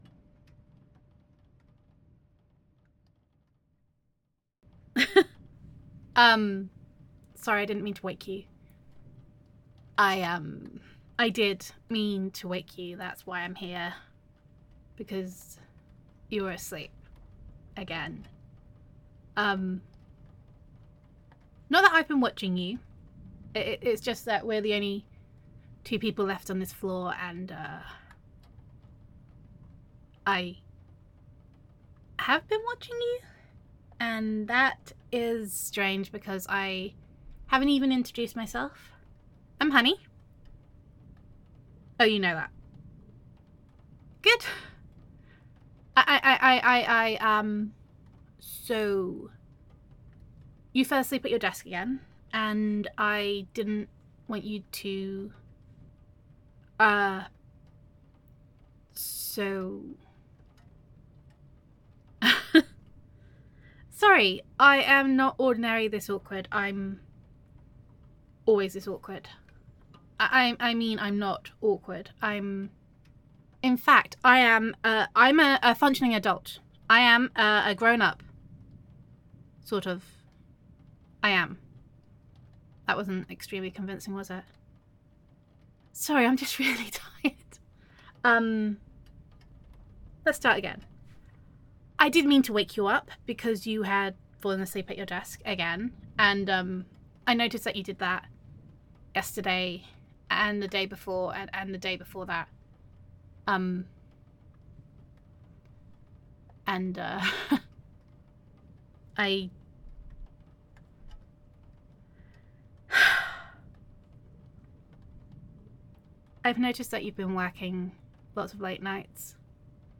[F4A] Sleeping at Your Desk [Office Crush][Sweet and Awkward][Working Late][Workaholic][Takeout][Corgi Pictures as Currency][Christmas][Co-Worker Roleplay][Gender Neutral][Your Office Crush Is Worried About You]